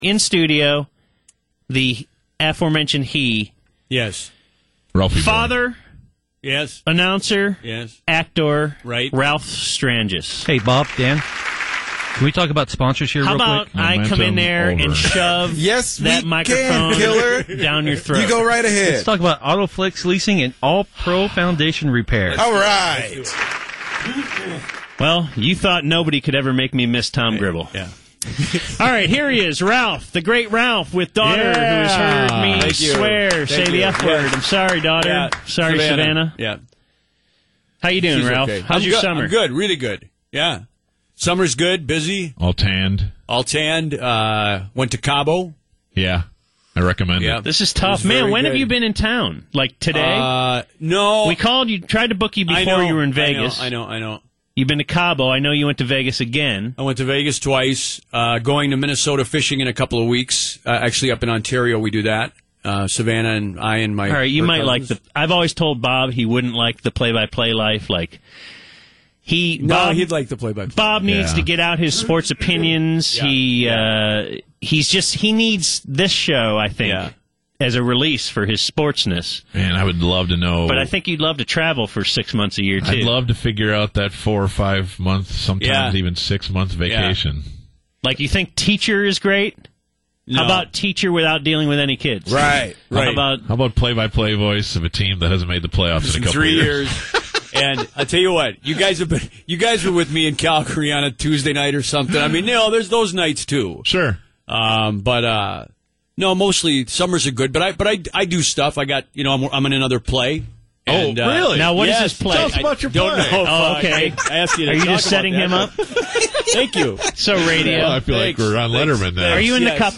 Interview topics included: